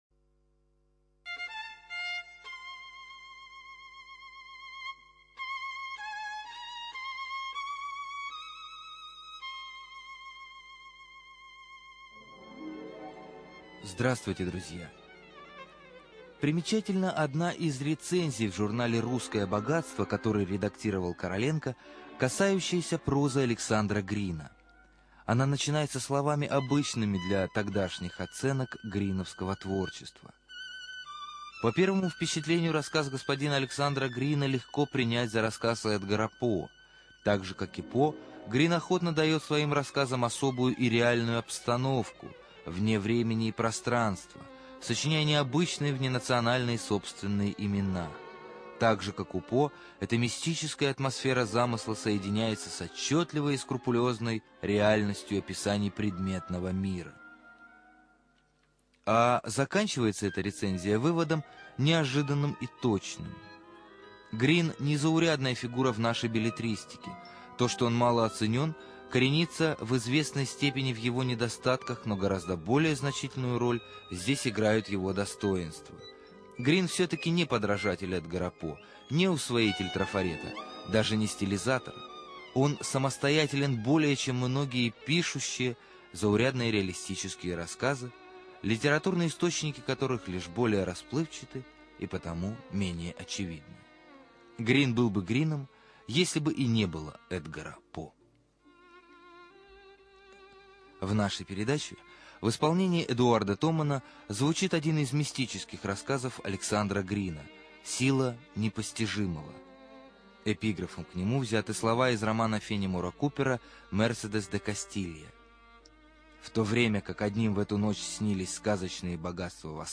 Студия звукозаписиРадио 4